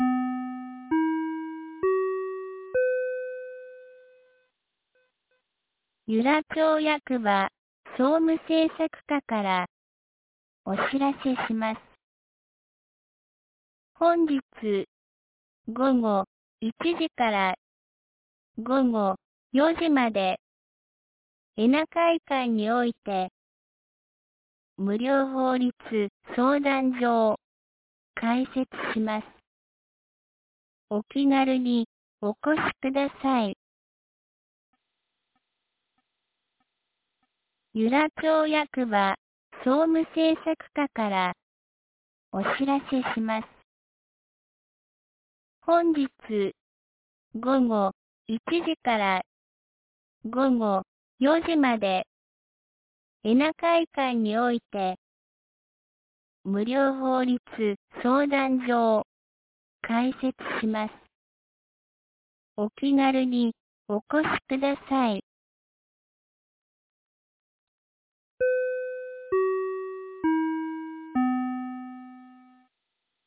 2024年08月08日 07時51分に、由良町から全地区へ放送がありました。